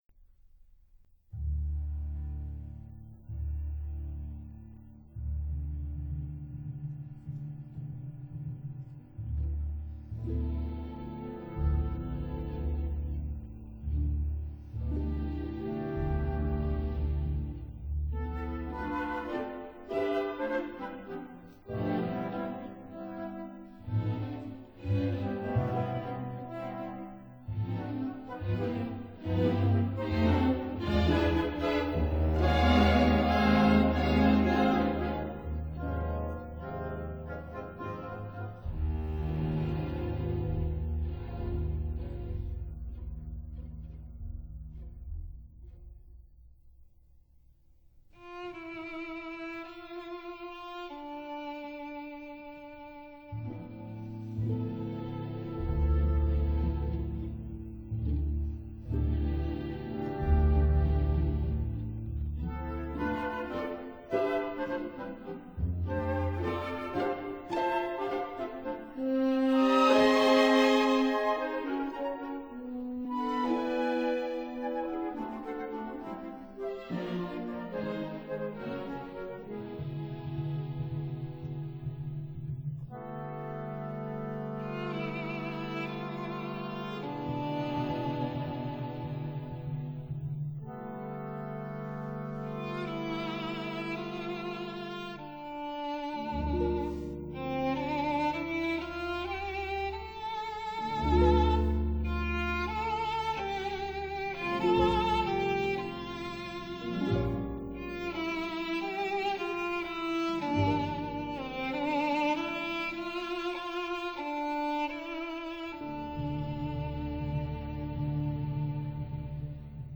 for violon et orchestre